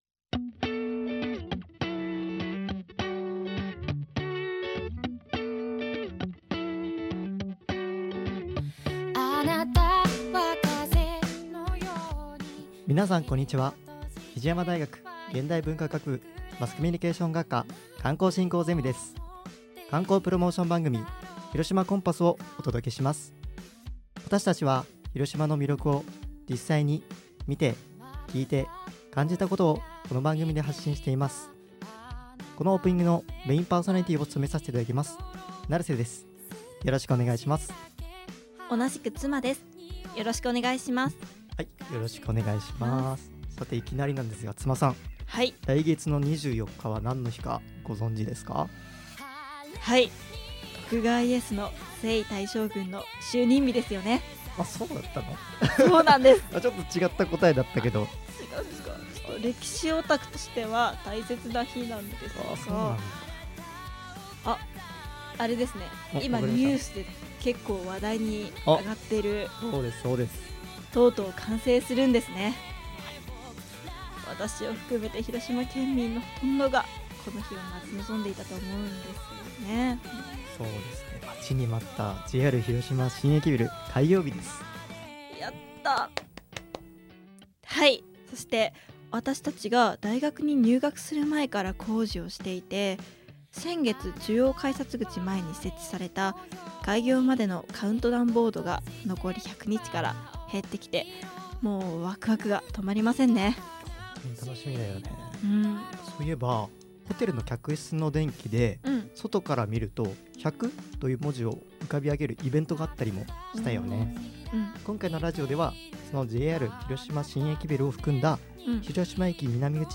比治山大学presents 観光プロモーションラジオ番組「広島コンパス2025」